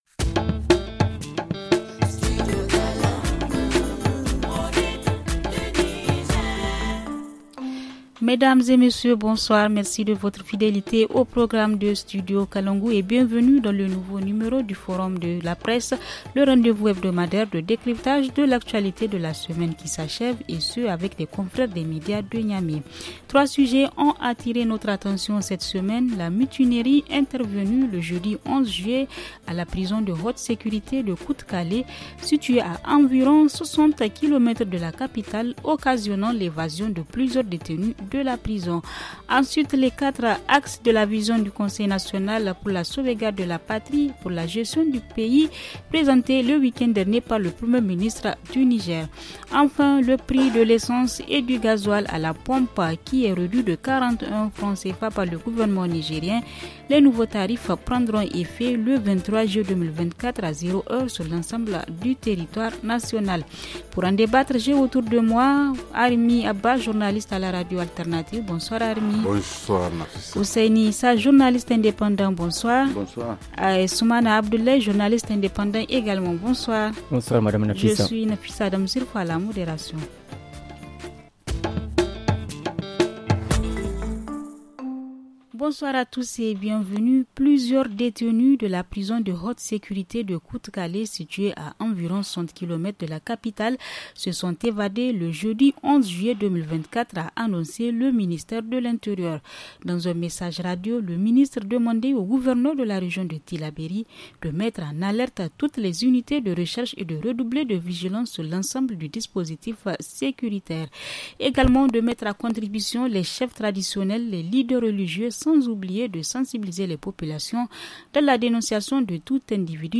Le forum de la presse du 19 juillet 2024 - Studio Kalangou - Au rythme du Niger